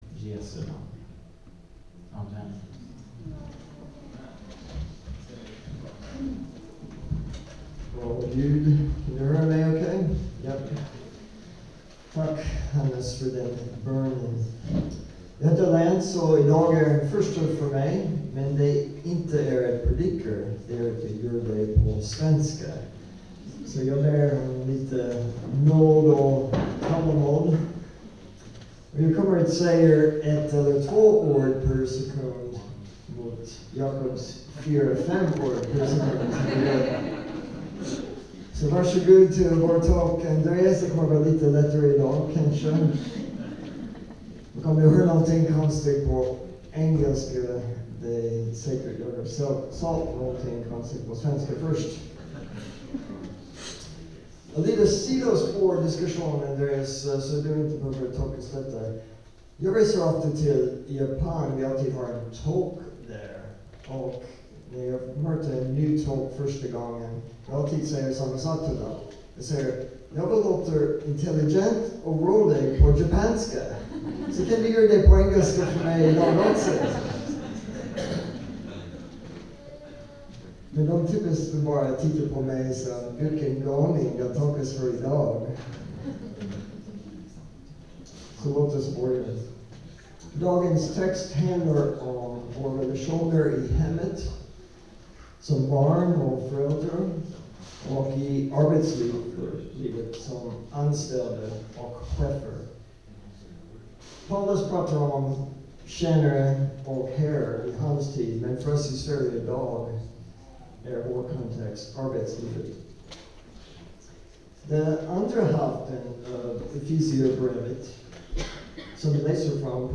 centro-predikan_2.mp3